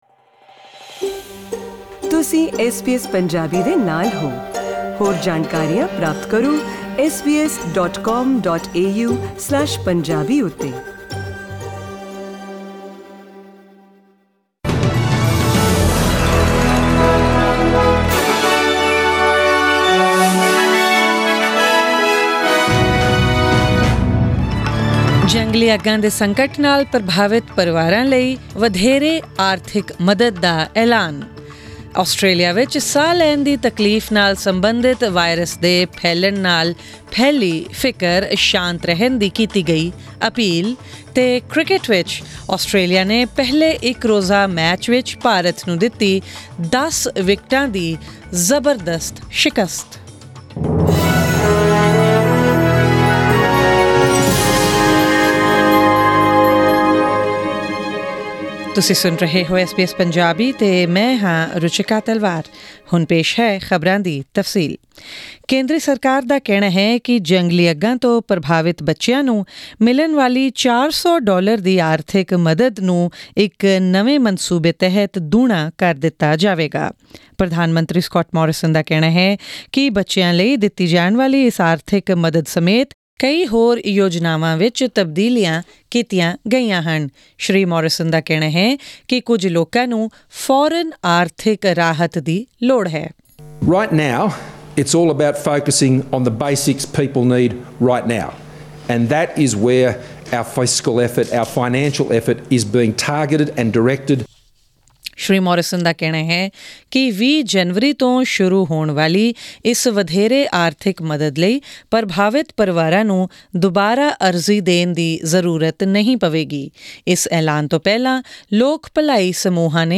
SBS Punjabi News: January 15, 2020